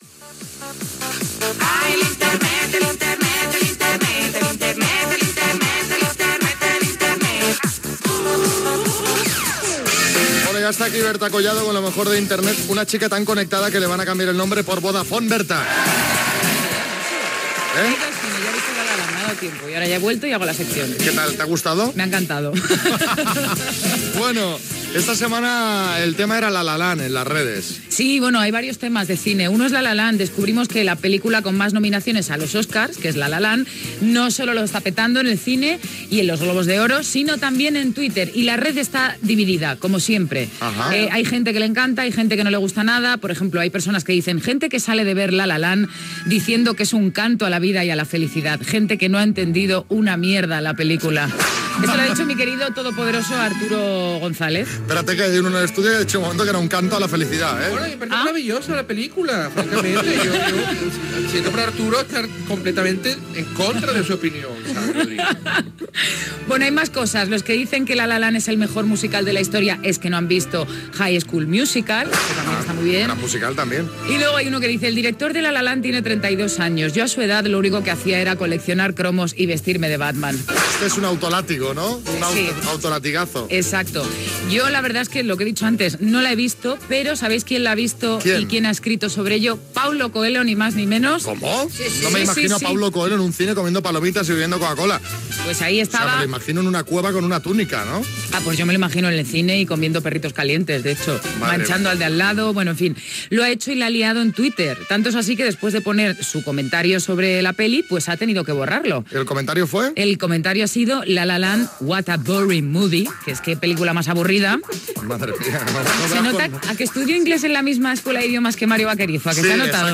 Entreteniment
Presentador/a